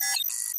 На этой странице собраны звуки уведомлений для электронной почты — короткие и четкие сигналы о новых письмах.
Звуковое уведомление о новой почте на электронке